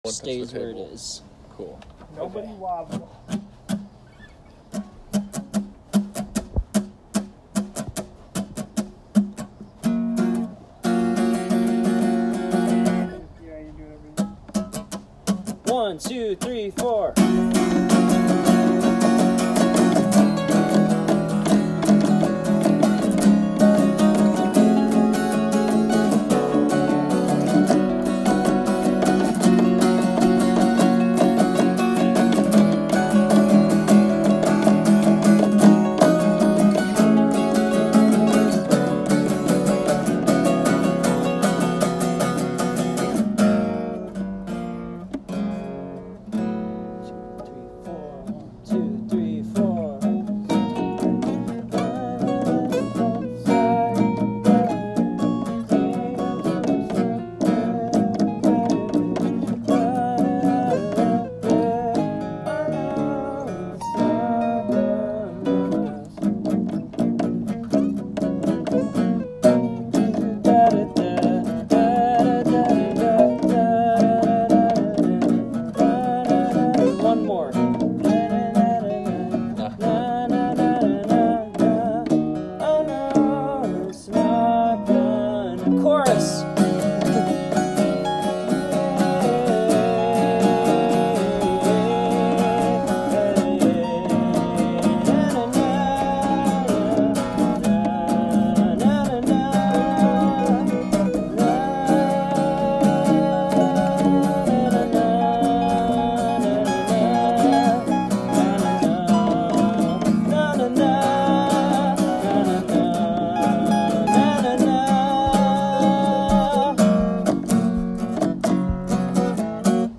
cul de sac acoustic.m4a